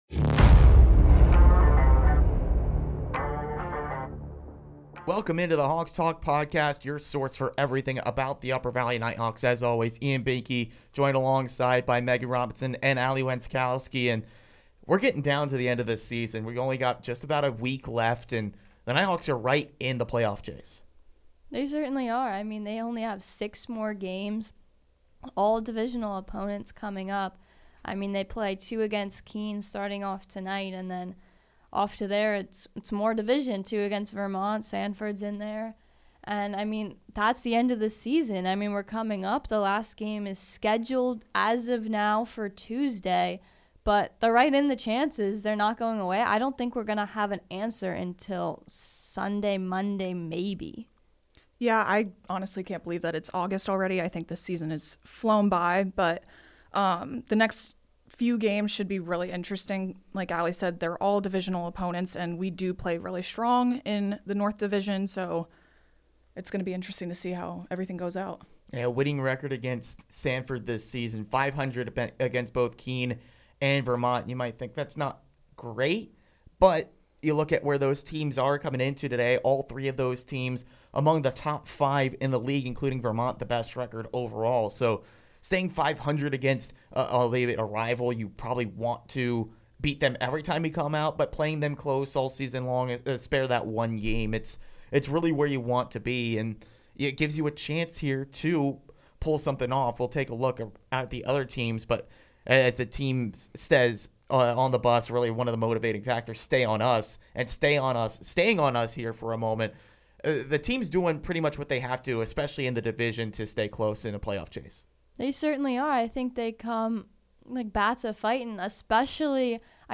Two members of the coaching staff sit down this week to talk about their approach to the season thus far and going into the last week of June.